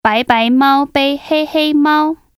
Record yourself reading it, then listen to how the speakers pronounce it.